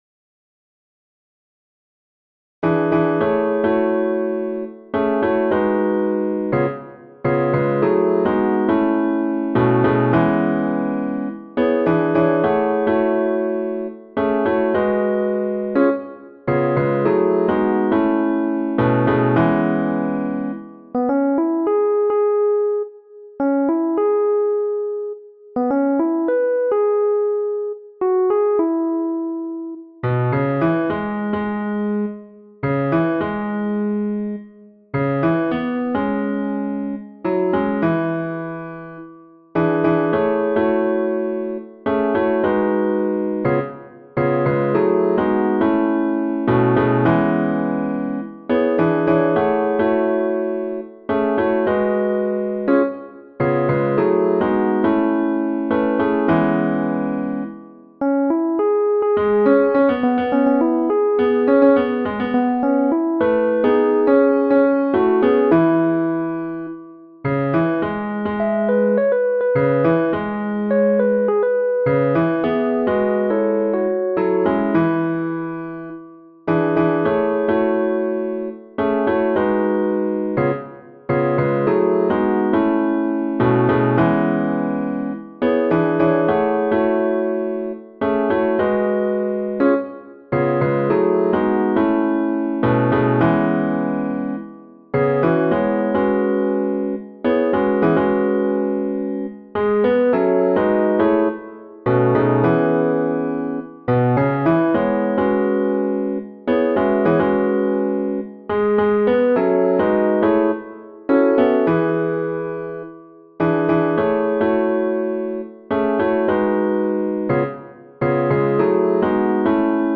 446 Ev’ry time I feel the Spirit Spiritual 2:30 min
Bas :
446_Bas_Evry_time_I_feel_the_Spirit.mp3